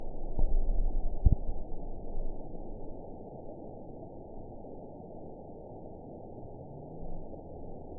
event 910709 date 01/30/22 time 03:36:58 GMT (3 years, 3 months ago) score 8.52 location TSS-AB01 detected by nrw target species NRW annotations +NRW Spectrogram: Frequency (kHz) vs. Time (s) audio not available .wav